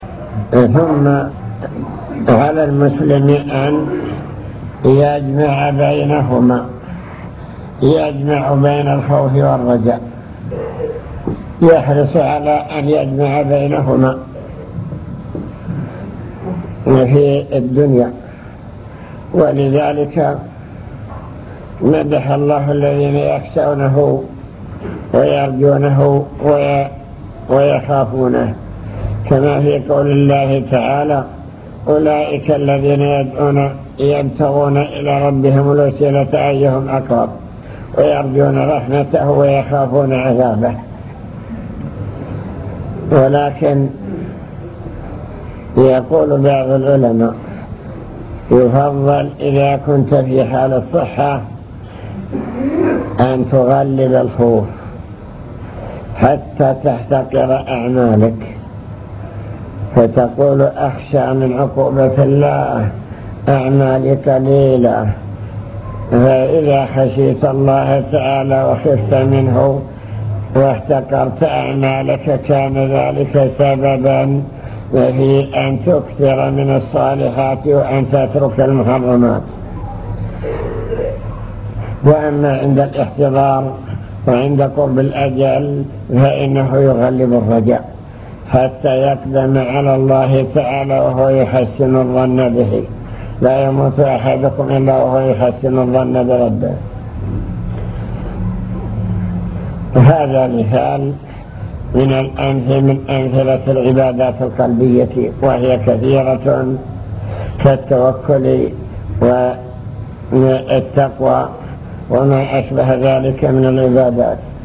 المكتبة الصوتية  تسجيلات - محاضرات ودروس  نوافل العبادات وأنواعها العبادات القولية